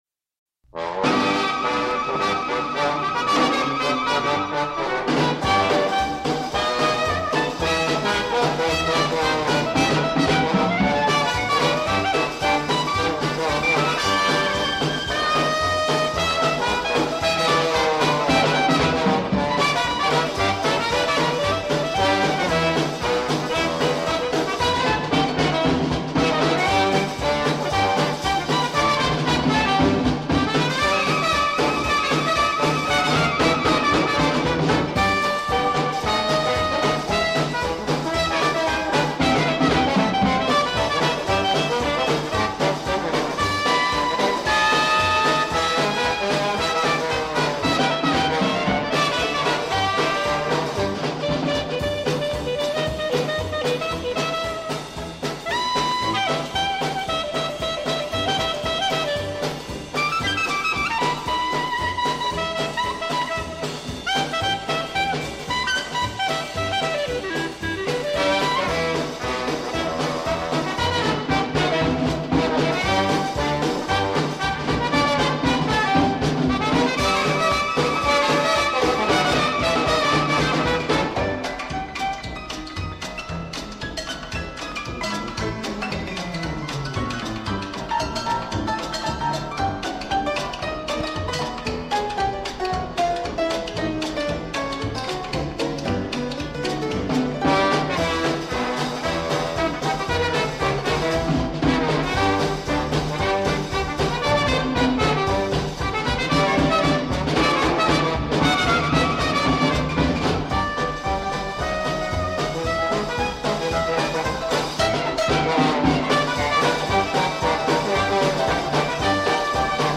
Dixieland